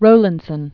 (rōlənd-sən), Thomas 1756-1827.